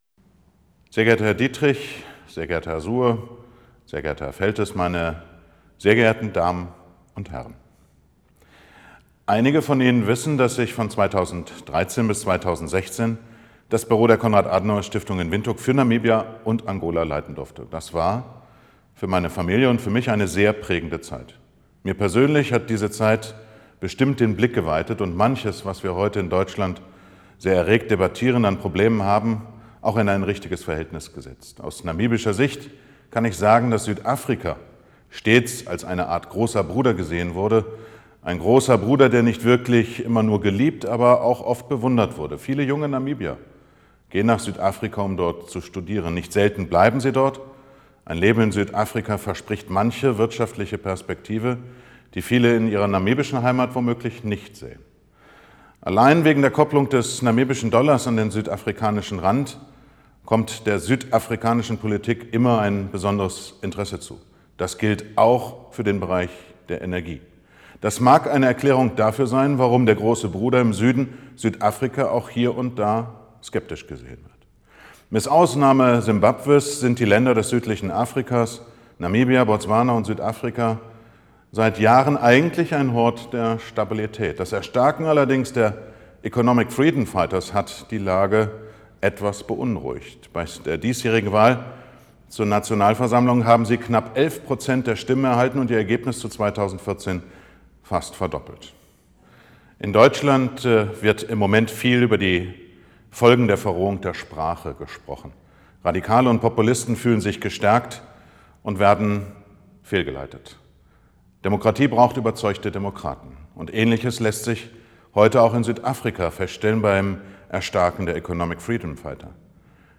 Das Grußwort von Minister Dr. Bernd Althusmann